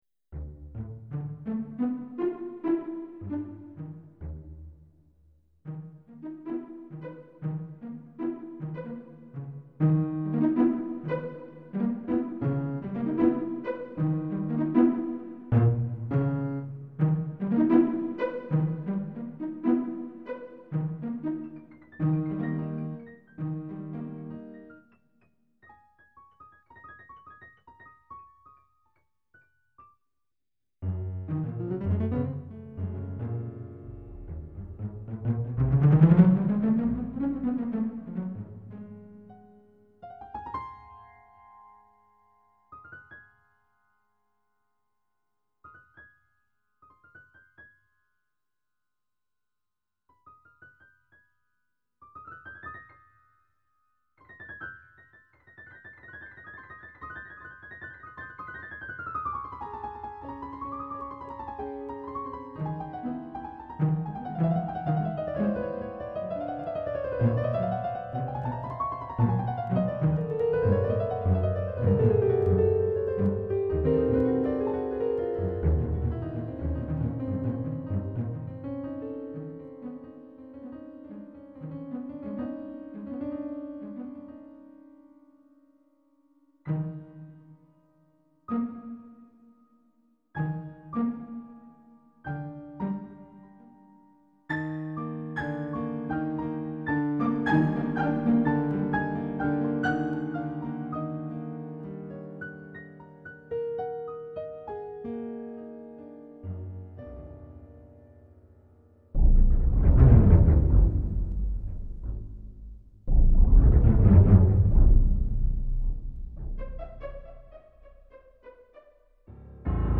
feeel-2 Subway | Improvised Music – DaDa Hypermedia
Subway-2 up & down | piano+strings staccato